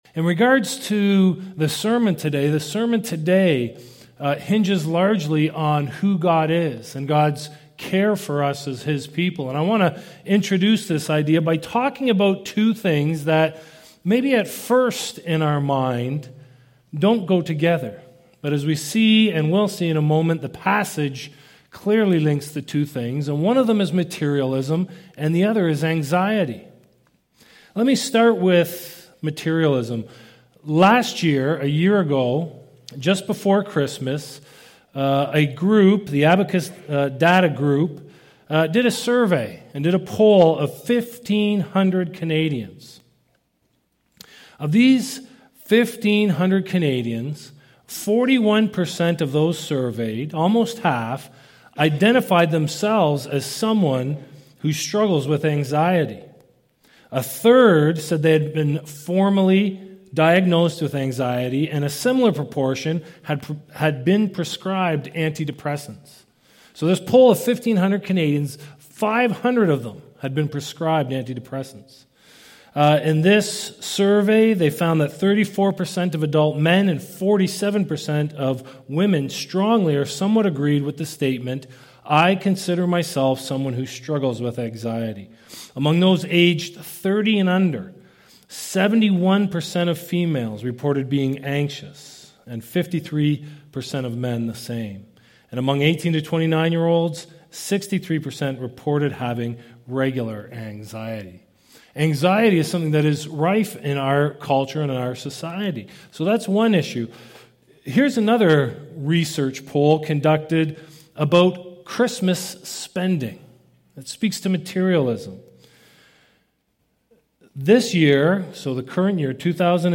Sermon Archives